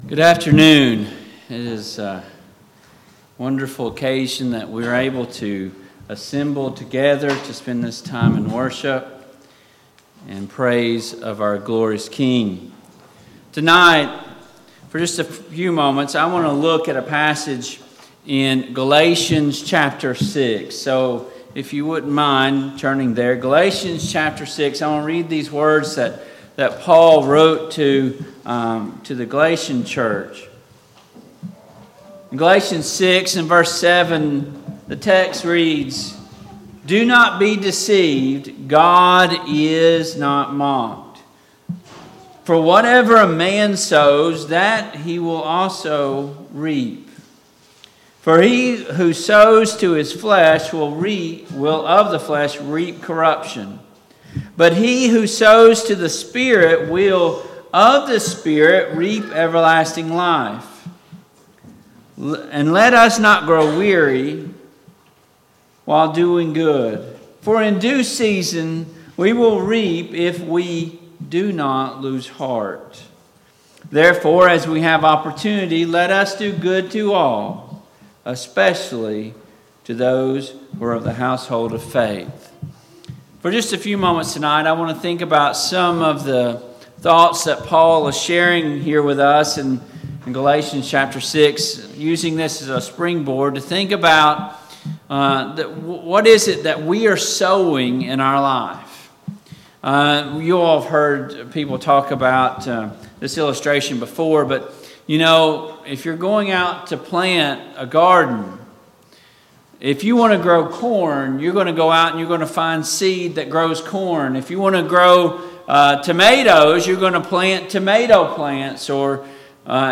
Service Type: PM Worship